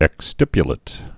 (ĕks-stĭpyə-lĭt)